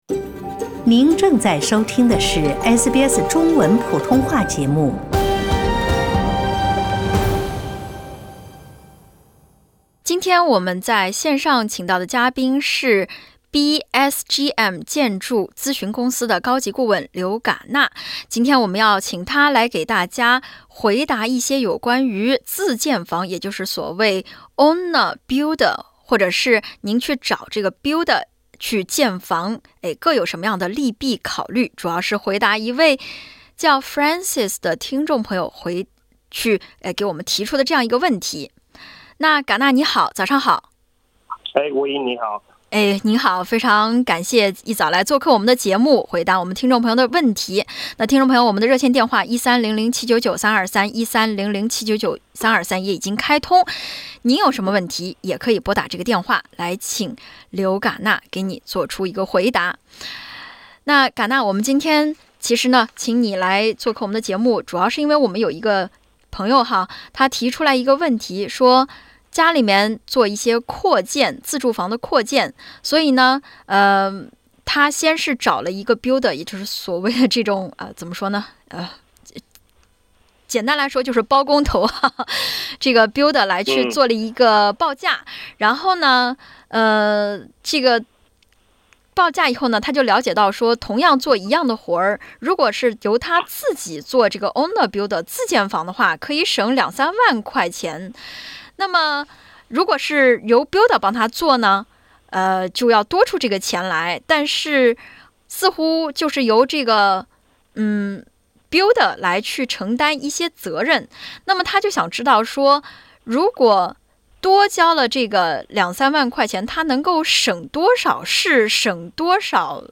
25:15 Source: AAP SBS 普通話電台 View Podcast Series Follow and Subscribe Apple Podcasts YouTube Spotify Download (46.26MB) Download the SBS Audio app Available on iOS and Android 自己建房子做owner builder，把每一分錢都花在刀刃上，聽起來似乎是一件特彆棒的事。